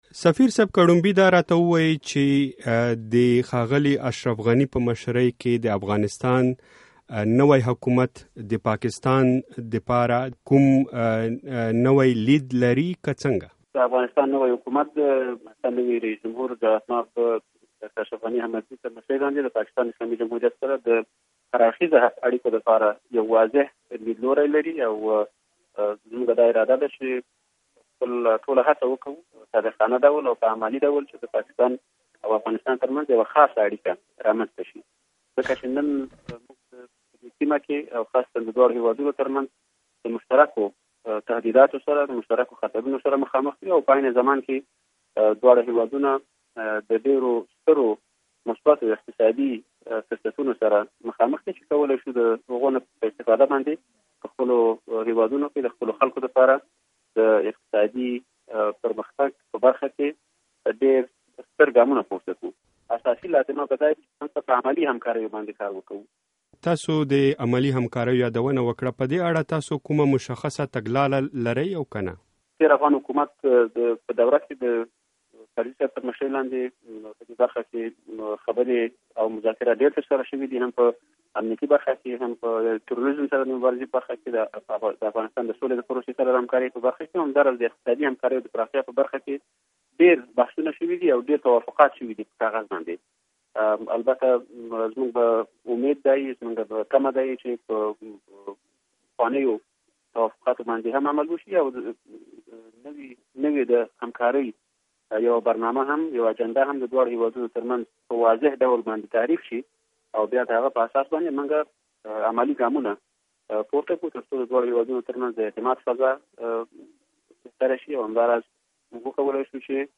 د افغانستان او پاکستان د اړیکو په اړه مرکه کړې.